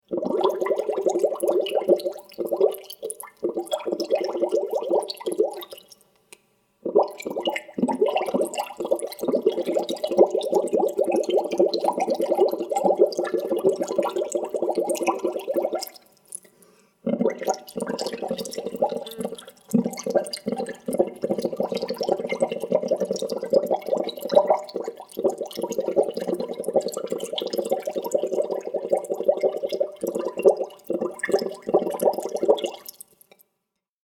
Burbujas en el agua: Efectos de sonido agua
La claridad y la nitidez de este efecto de sonido te ofrecen una gran versatilidad creativa.
Este efecto de sonido ha sido grabado para capturar la naturalidad del proceso de burbujeo en el agua, proporcionando un sonido claro y distintivo que se integrará perfectamente en tus proyectos.
Tipo: sound_effect
Burbujas en el agua.mp3